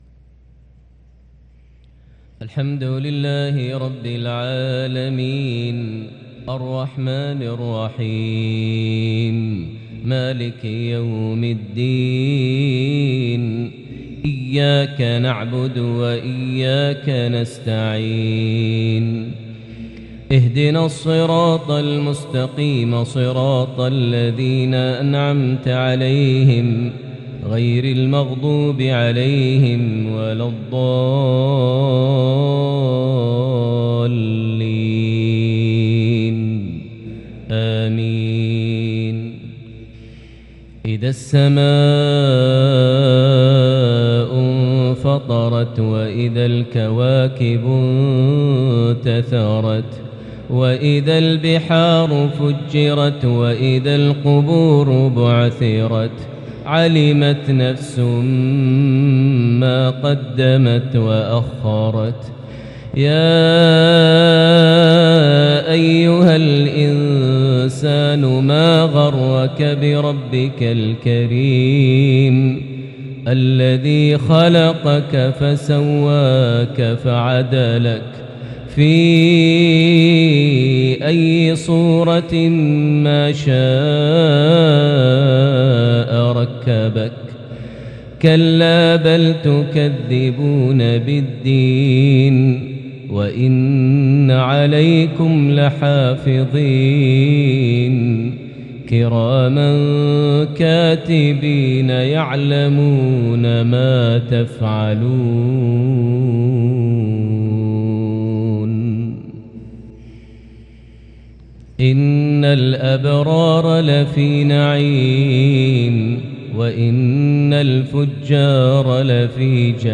maghrib 8-5-2022 prayer from Surah AL-Infitar > 1443 H > Prayers - Maher Almuaiqly Recitations